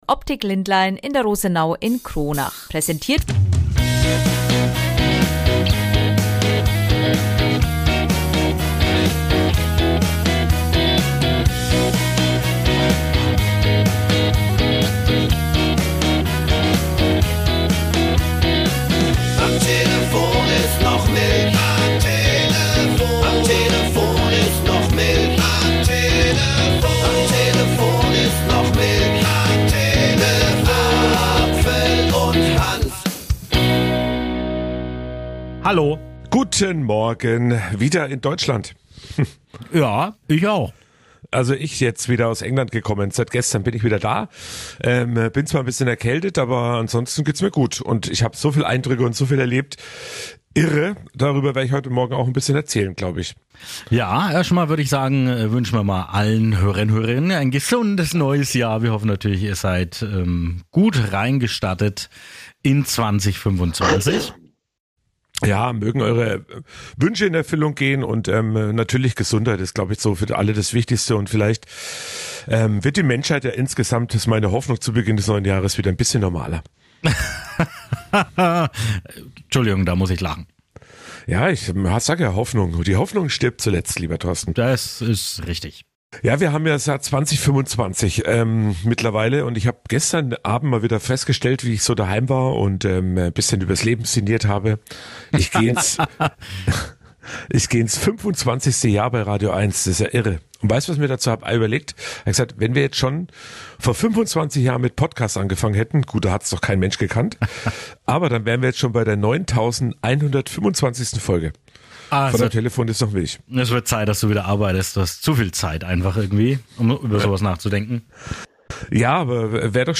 Dazu gibt es viele Berichte und Interviews